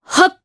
Priscilla-Vox_Casting1_jp.wav